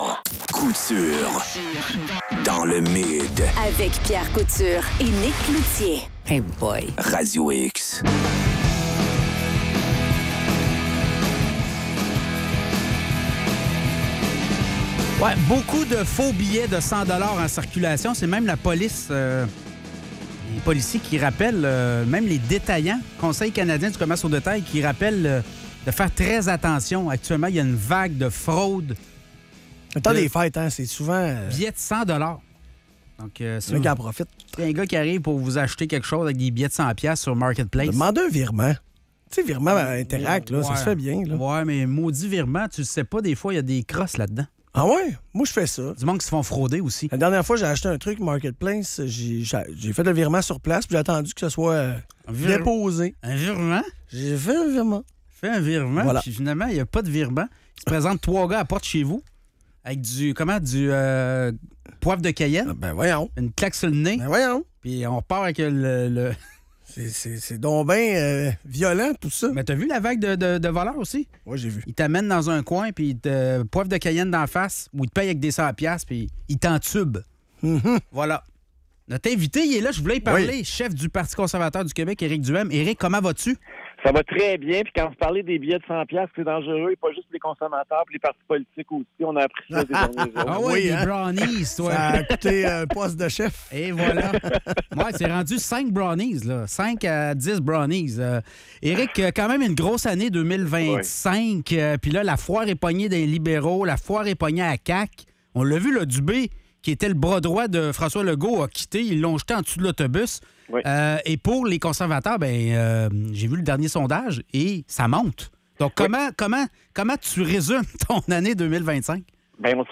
Entrevue avec Éric Duhaime